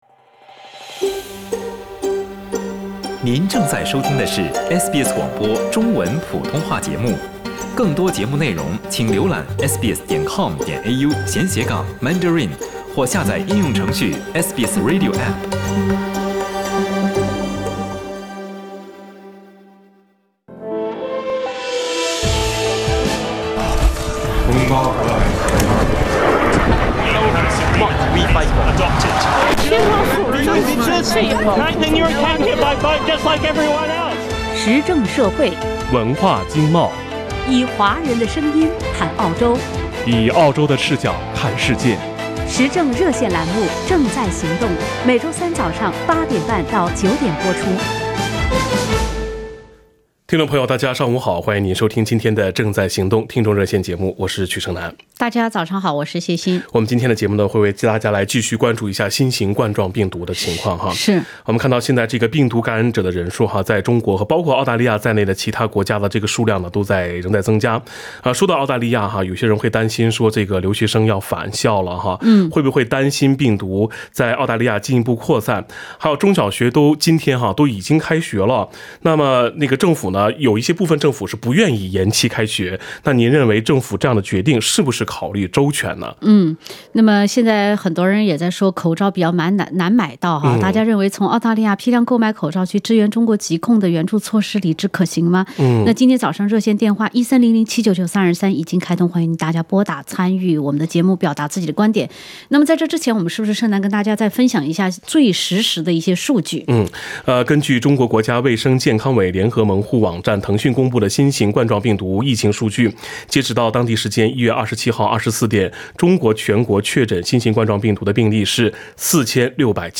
参与《正在行动》热线的听众反映了他们近期购买口罩的情况：一罩难求，坐地起价。